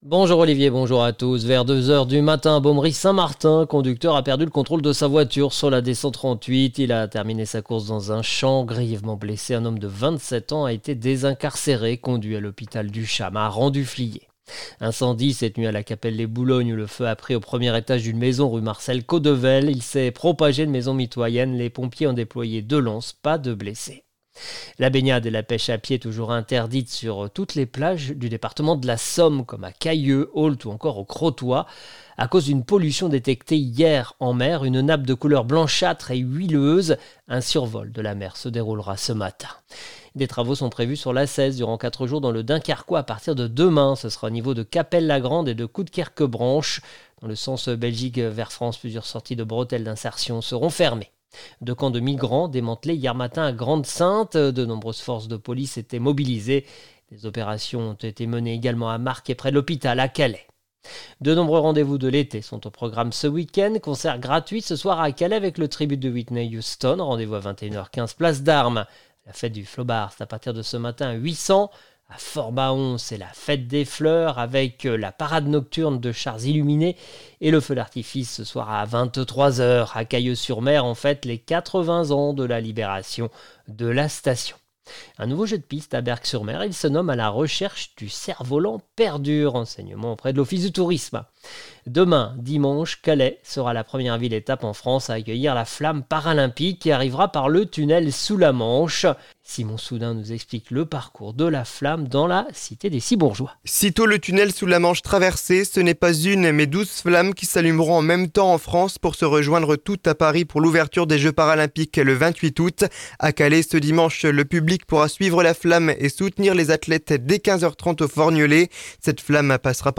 (journal de 9h)